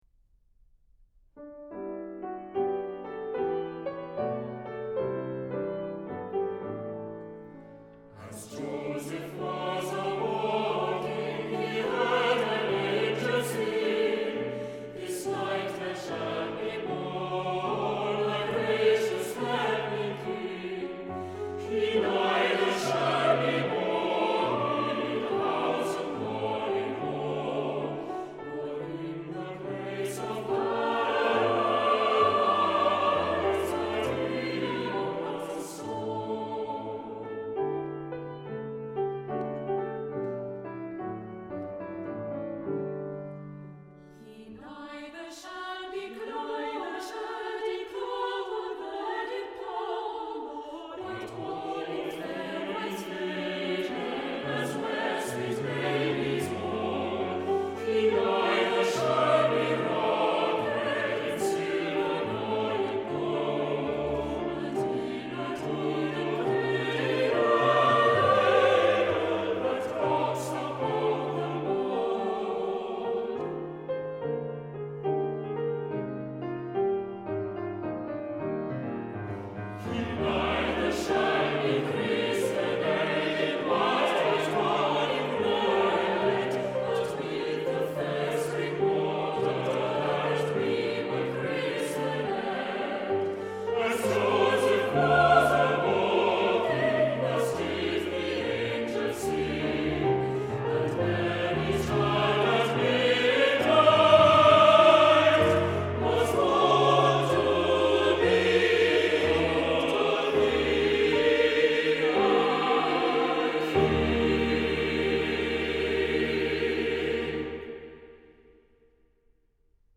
SATB a cappella Level